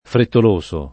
[ frettol 1S o ]